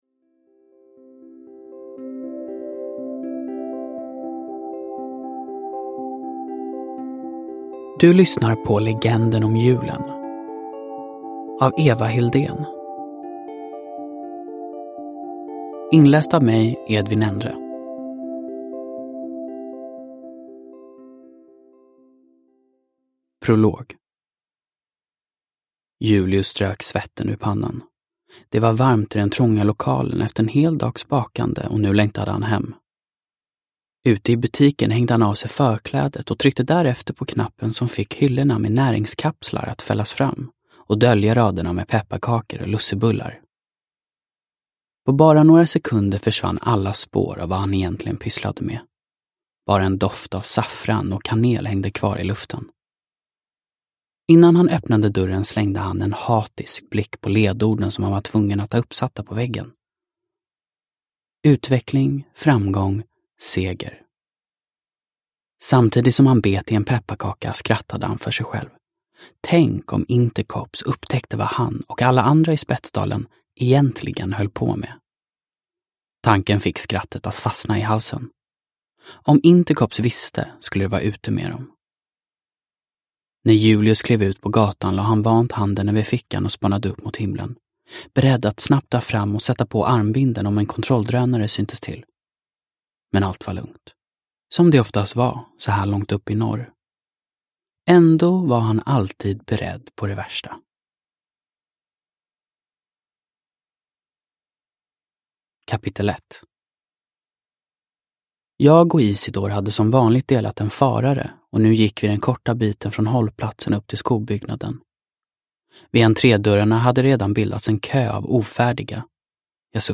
Legenden om julen – Ljudbok – Laddas ner